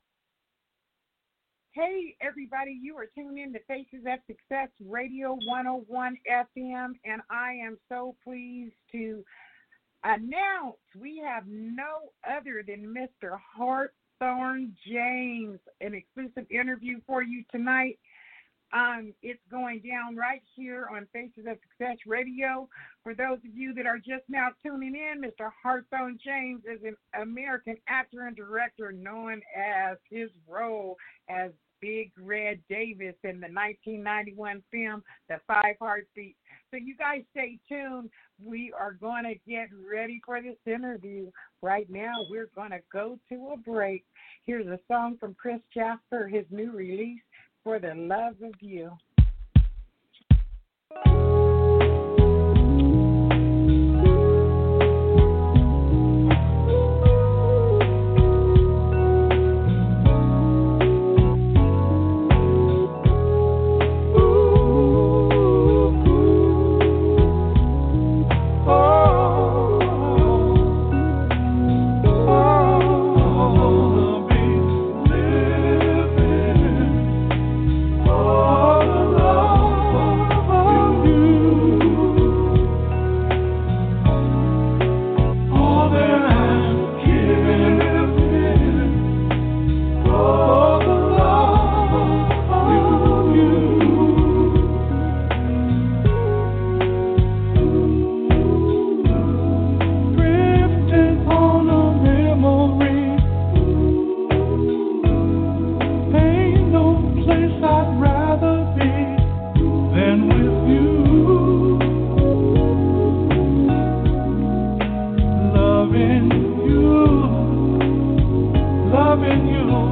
Hawthorne James - Exclusive Interview When Thurs, Sept 3, 2020 - 7pm PST